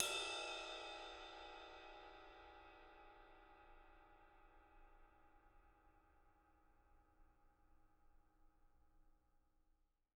R_B Ride 01 - Room.wav